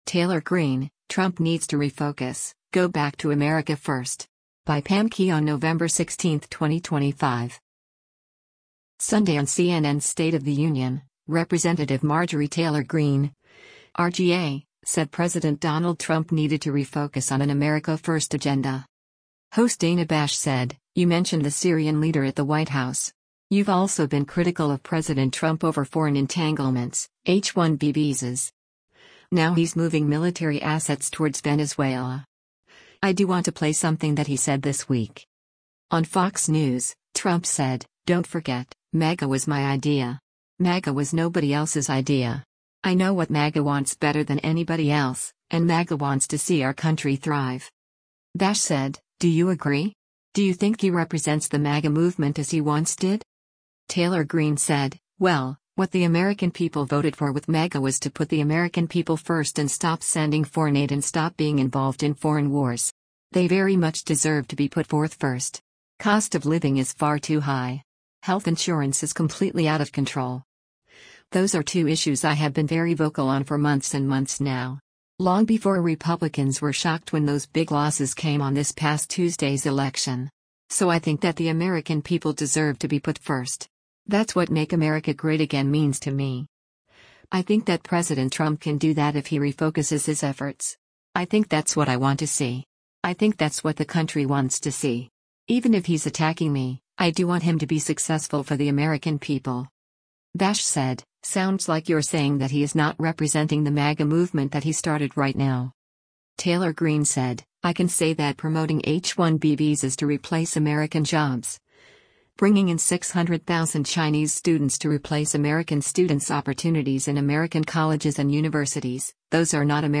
Sunday on CNN’s “State of the Union,” Rep. Marjorie Taylor Greene (R-GA) said President Donald Trump needed to “refocus” on an “America first” agenda.